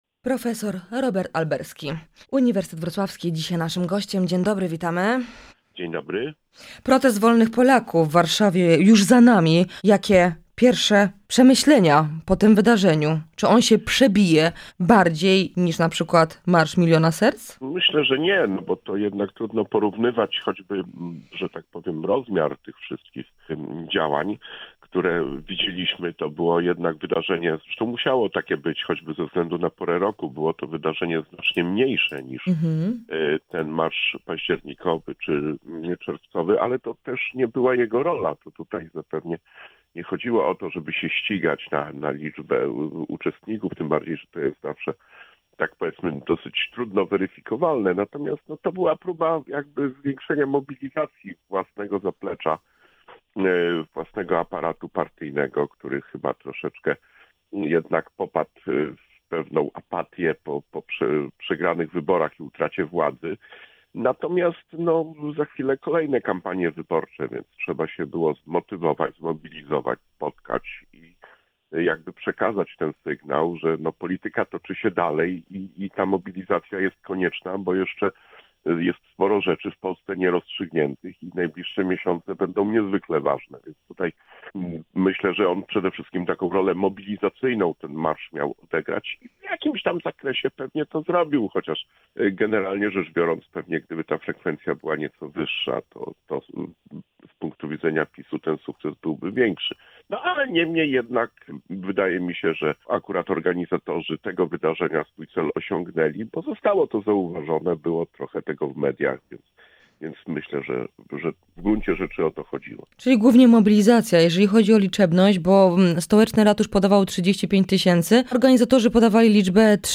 Z politologiem rozmawiamy także o dalszych losach Kamińskiego i Wąsika oraz najbliższych wydarzeniach w Sejmie.